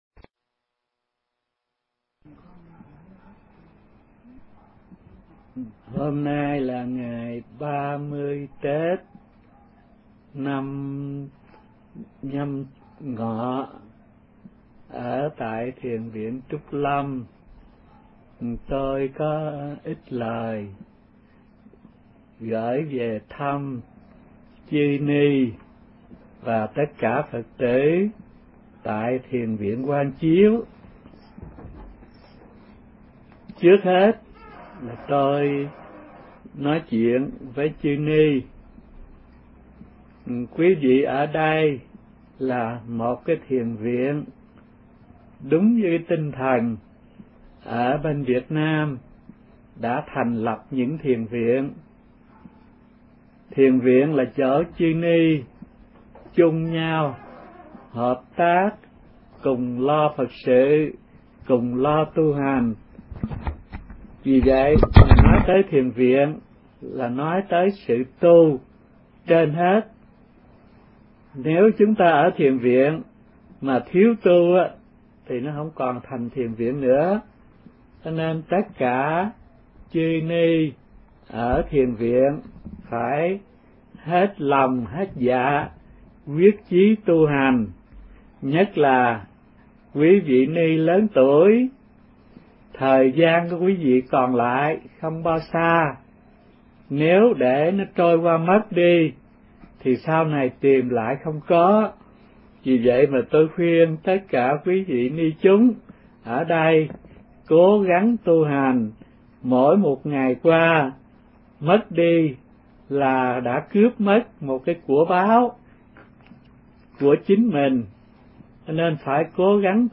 Hòa Thượng Thích Thanh Từ Nói Chuyện Tại Thiền Viện Quang Chiếu